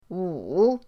wu3.mp3